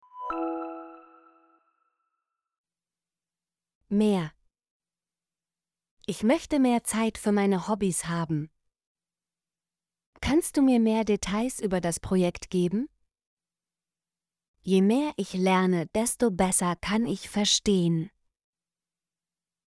mehr - Example Sentences & Pronunciation, German Frequency List